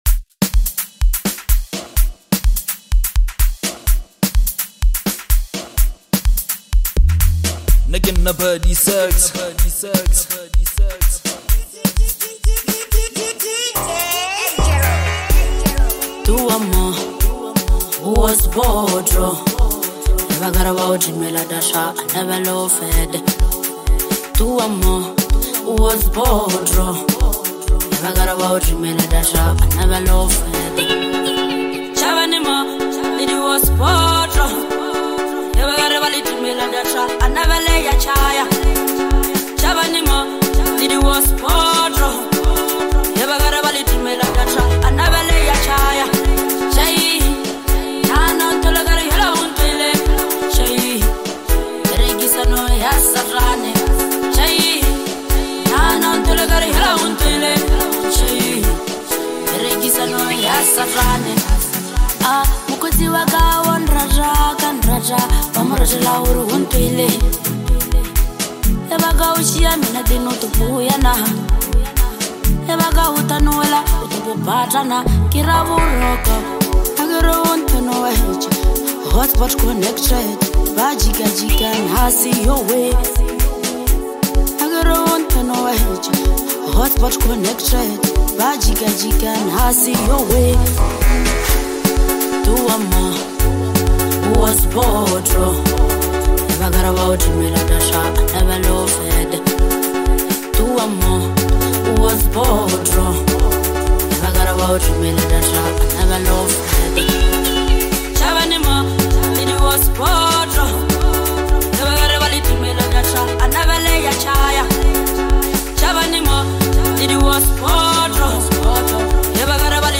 Home » Amapiano
South African singer-songwriter